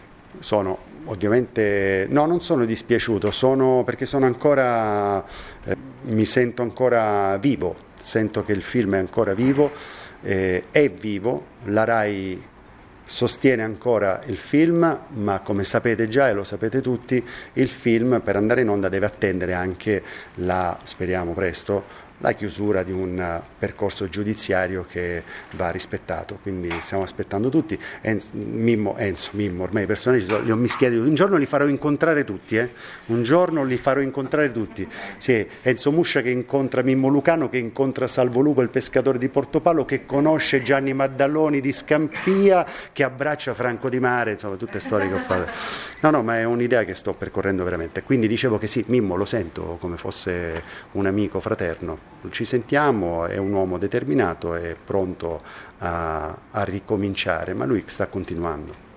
il-mondo-sulle-spalle-beppe-fiorello-parla-di-mimmo-luciano.mp3